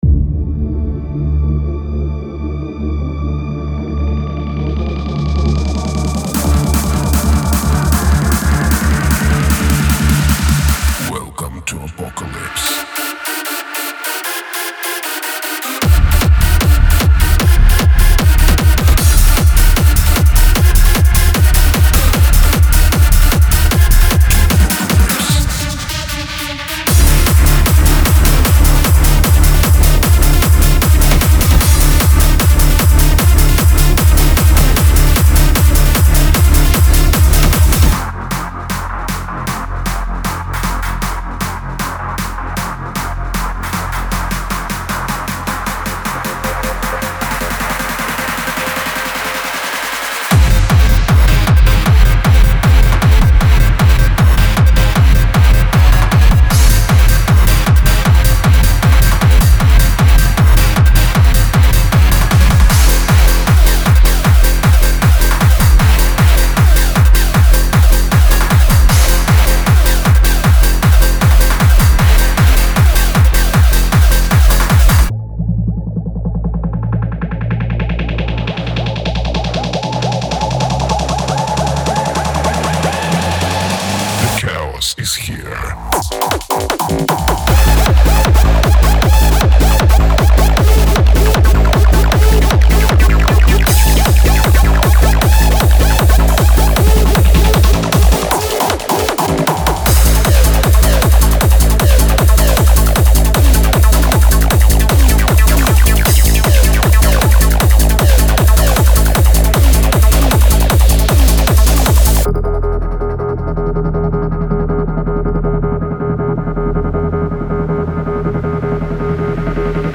Hardcore / Hardstyle Techno
7 x Full Top Quality Hard Techno Construction Kits.
BPM 152-160.